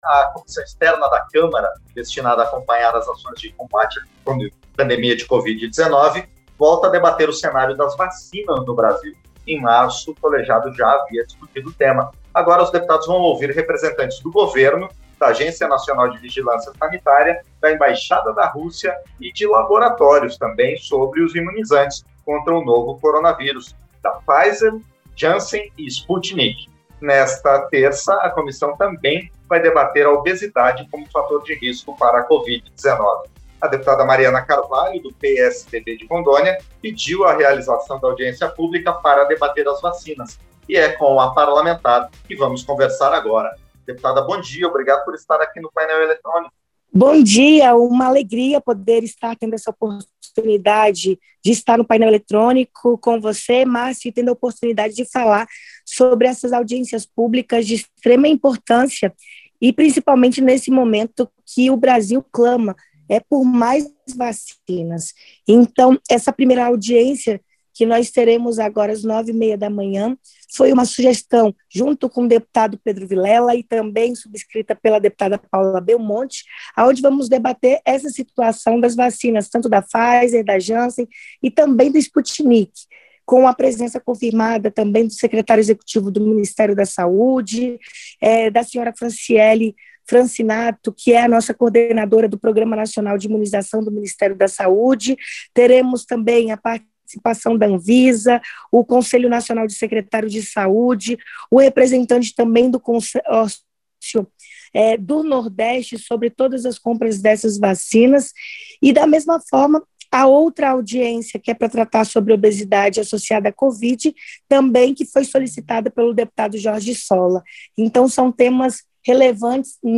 Entrevista - Dep. Mariana Carvalho (PSDB-RO)